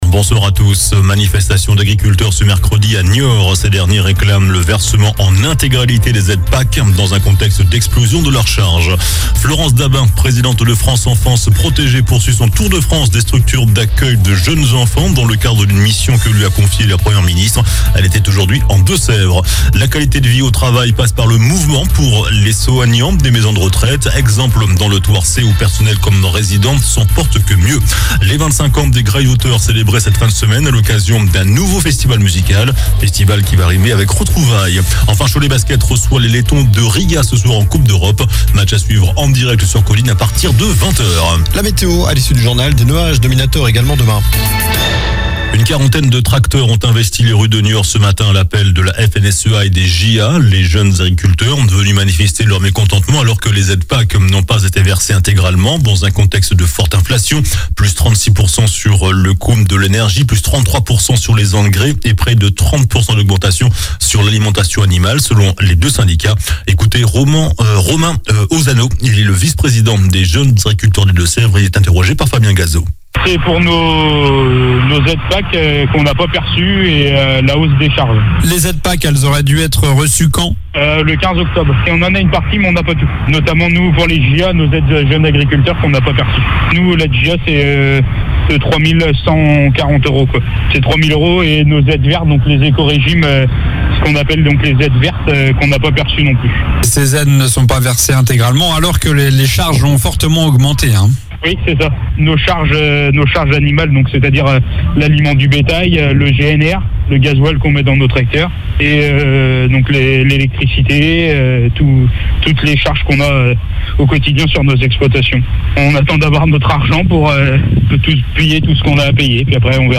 JOURNAL DU MERCREDI 25 OCTOBRE ( SOIR )